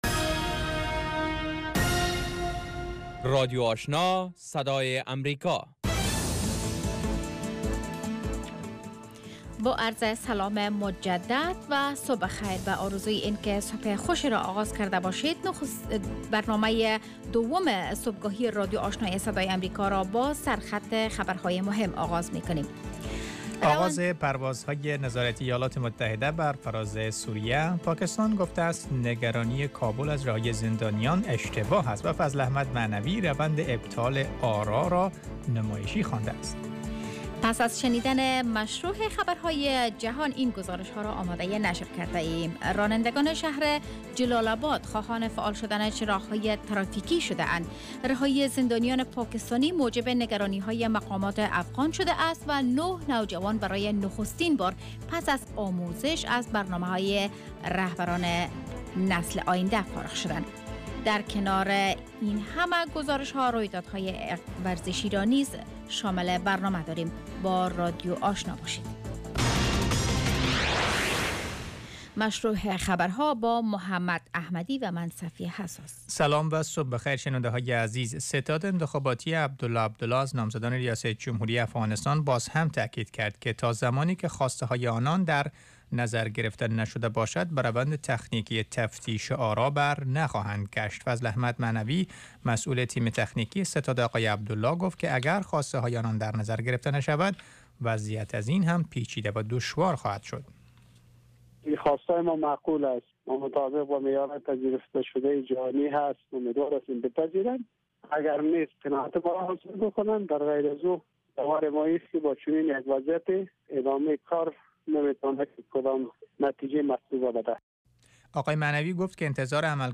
دومین برنامه خبری صبح
morning news show second part